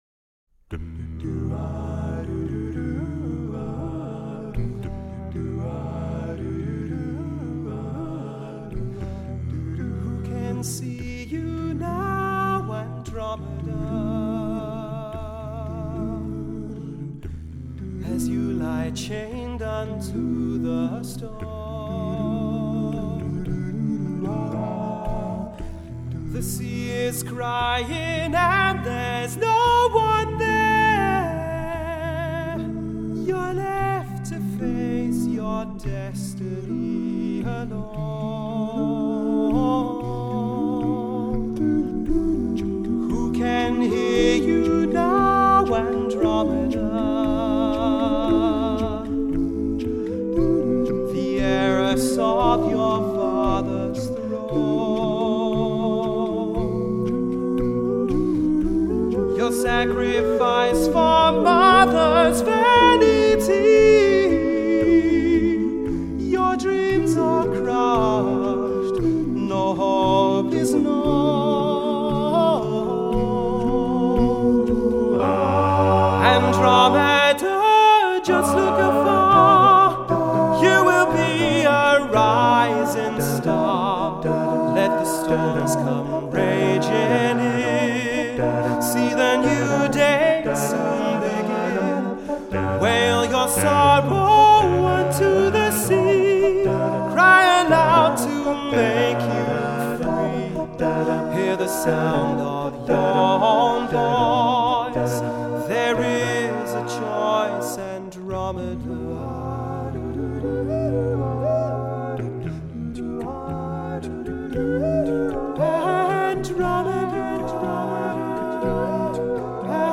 Voicing: SATB divisi a cappella